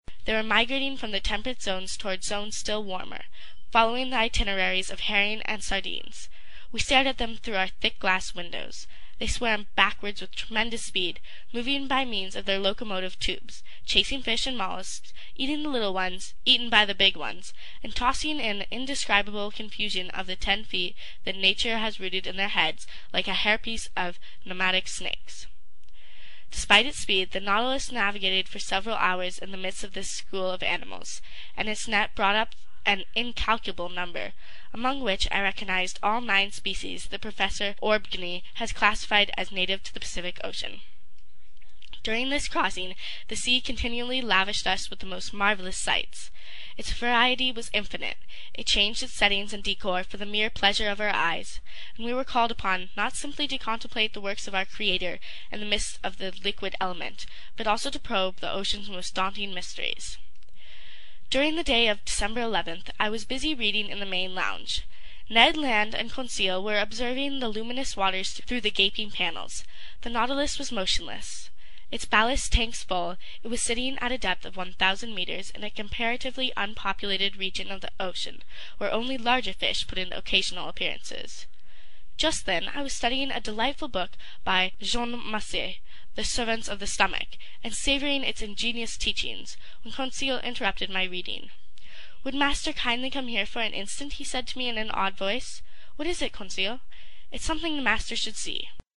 在线英语听力室英语听书《海底两万里》第237期 第18章 太平洋下四千里(9)的听力文件下载,《海底两万里》中英双语有声读物附MP3下载